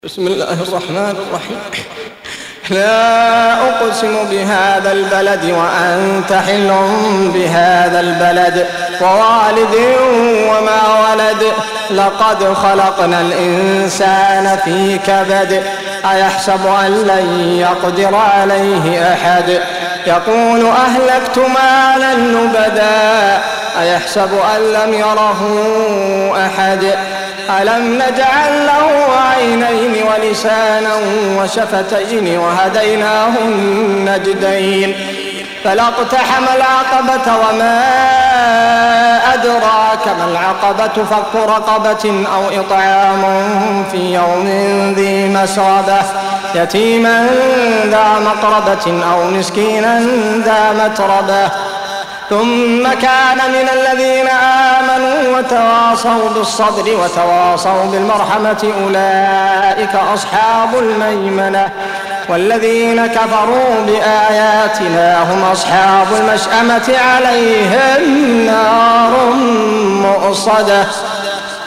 Surah Sequence تتابع السورة Download Surah حمّل السورة Reciting Murattalah Audio for 90. Surah Al-Balad سورة البلد N.B *Surah Includes Al-Basmalah Reciters Sequents تتابع التلاوات Reciters Repeats تكرار التلاوات